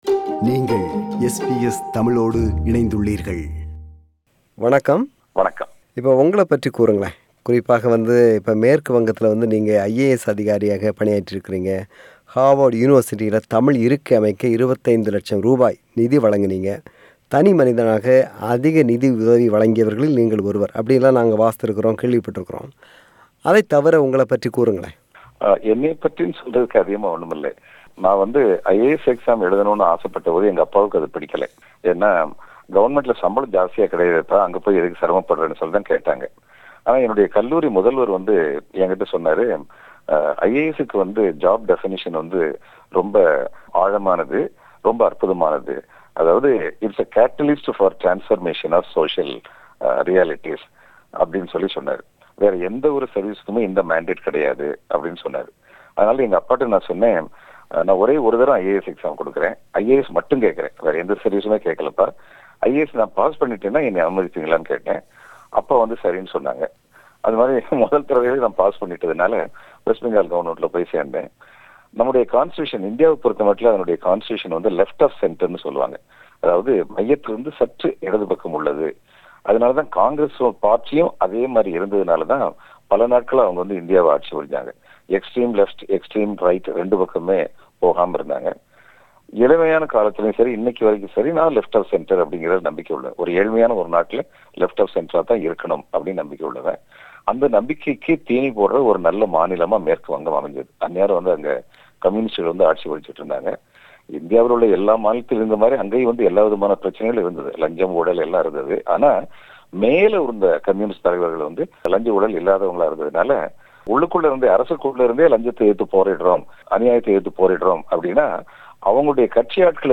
ஆனால் தமிழ் மொழி, தமிழ் இனம், சாதி, தமிழரின் வணிகம், விமர்சனம், திறனாய்வு இல்லாத தமிழிலக்கியம் என்று பல அம்சங்கள் குறித்து மேடைகளில் கடுமையான விமரசனங்களை முன்வைத்து வருகிறார். அவரோடு ஒரு மனம் திறந்த உரையாடல்.